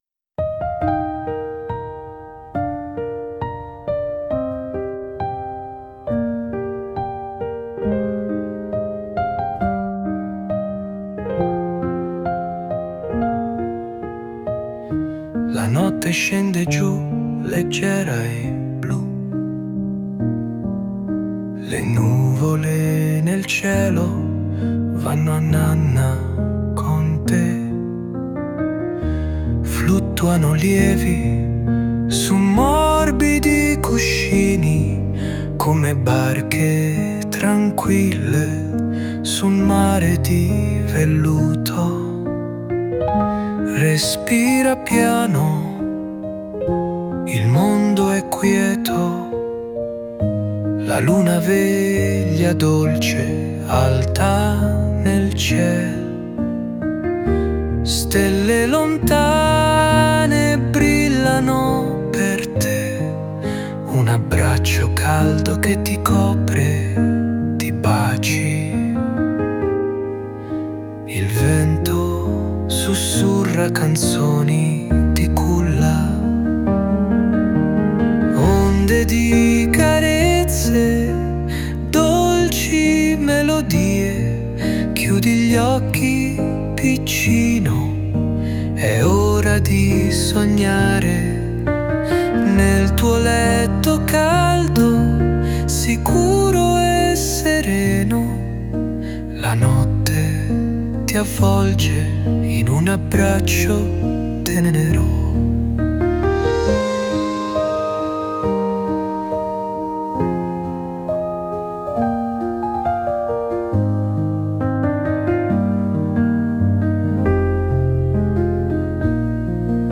Stella stellina, una delle ninne nanne più amate: dolce e ripetitiva, con le storie di animali che dormono accanto alla loro mamma.